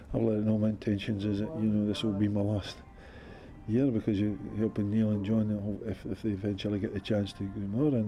Last week, 64 year old Jefferies told us he was planning to leave this year if they got promoted: